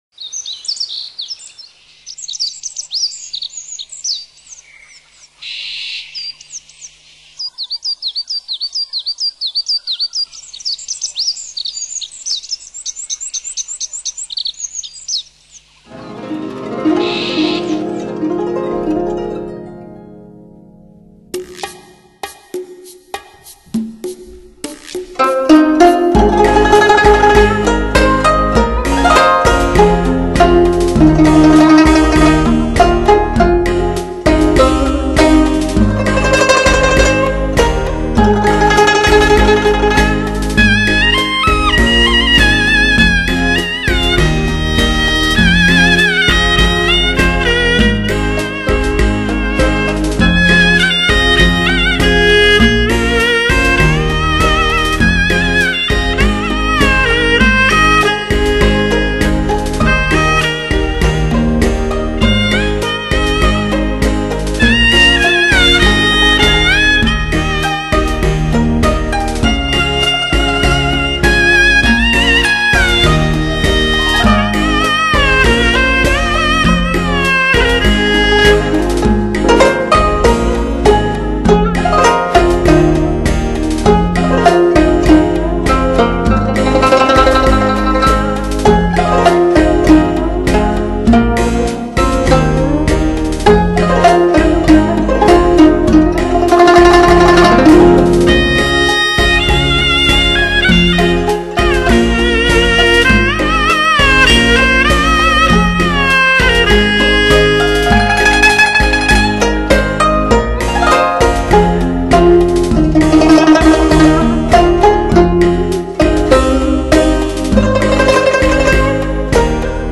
高胡